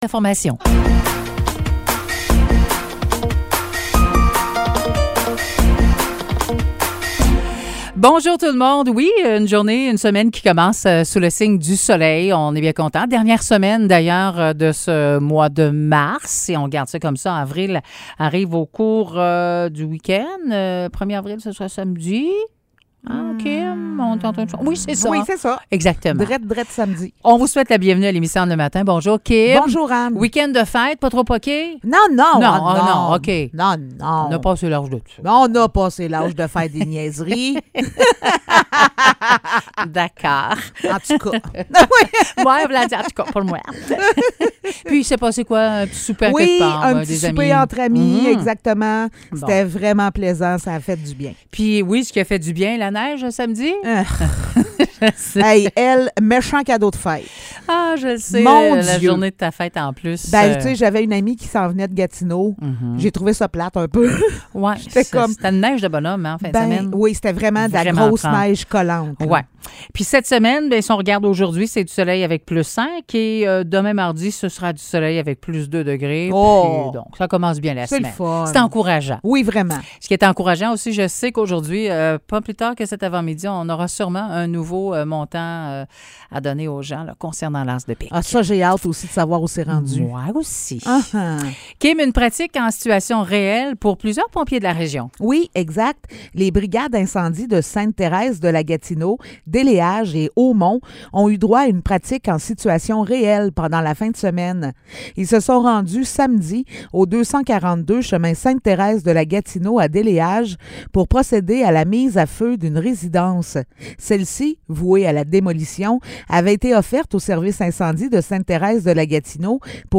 Nouvelles locales - 27 mars 2023 - 9 h